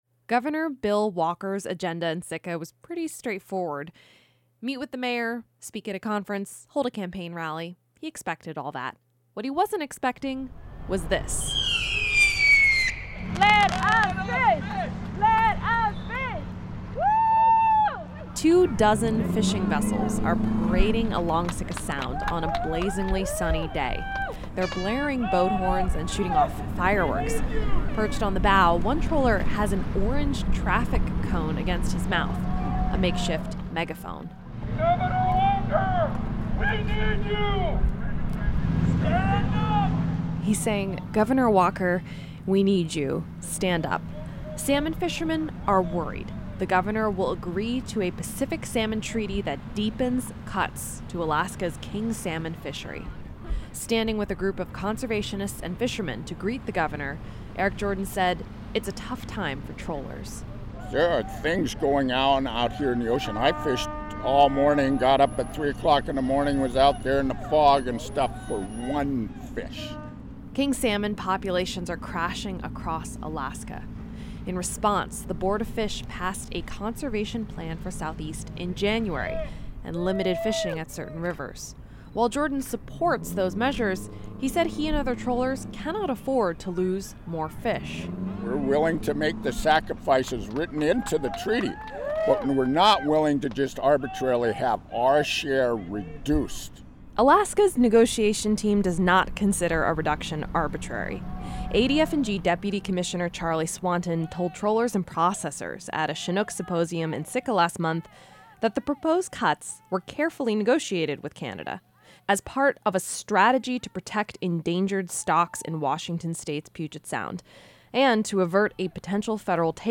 (Fireworks crackle)
Three dozen fishing vessels are parading along Sitka Sound on a blazing, sunny day. They’re blaring boat horns and shooting off fireworks.